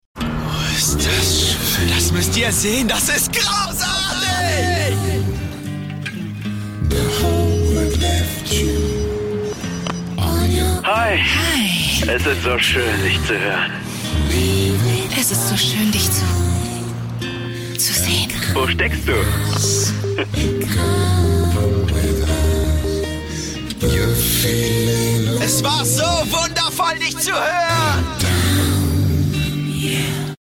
Vodaphone | sinnlich | 0.30